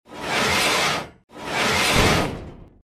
garage.mp3